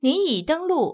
ivr-you_are_now_logged_in.wav